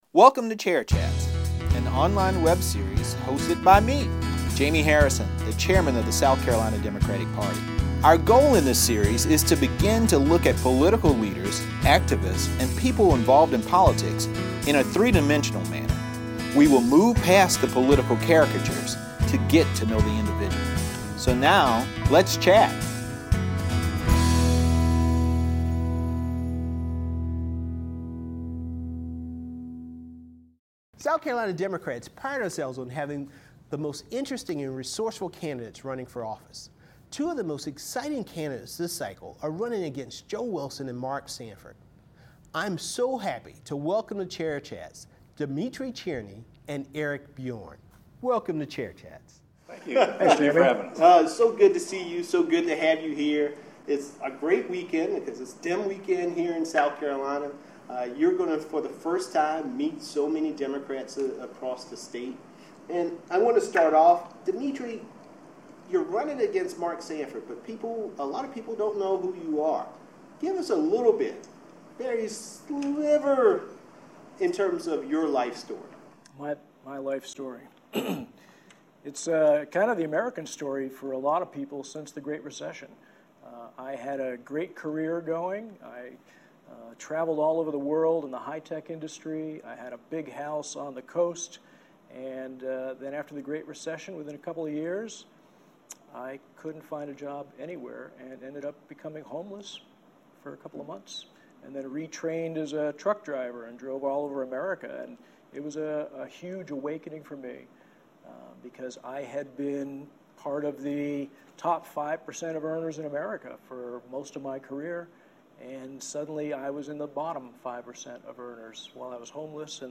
chats with two South Carolina congressional candidates